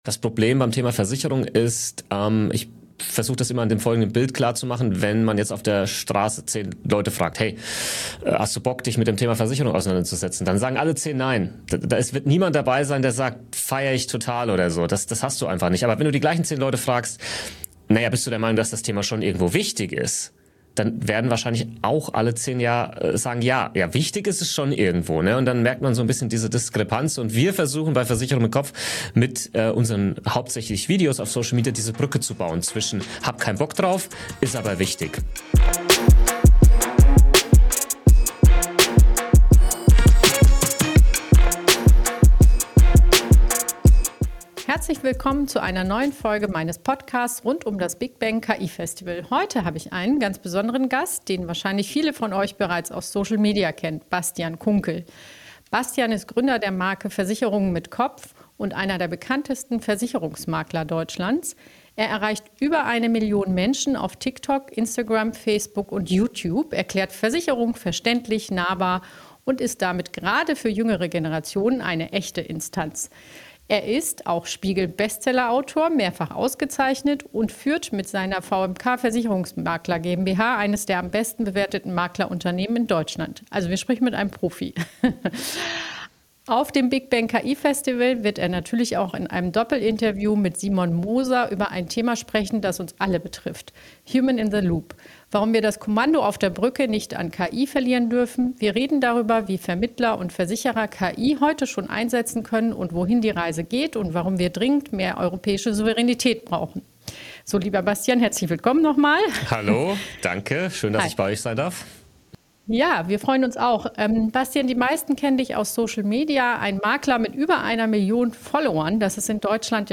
Ein inspirierendes Gespräch über Disruption, Verantwortung und die Zukunft einer Branche, die uns alle betrifft.